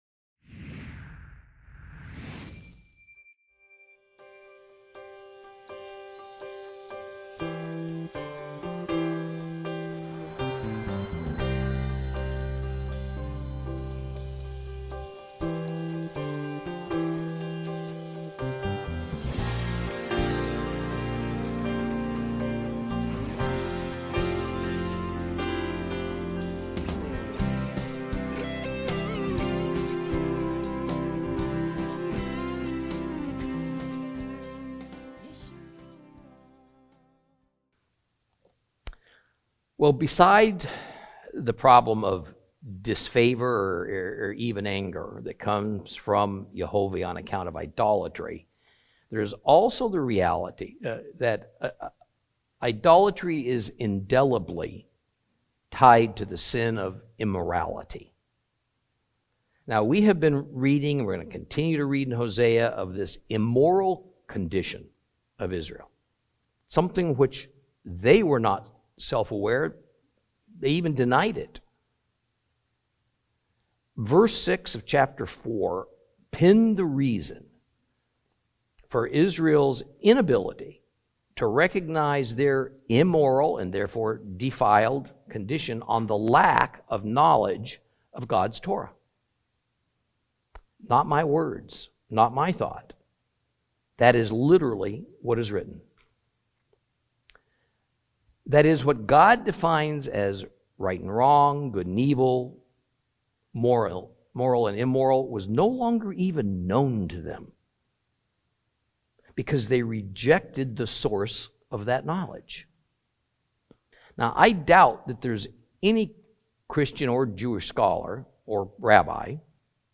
Teaching from the book of Hosea, Lesson 9 Chapter 5.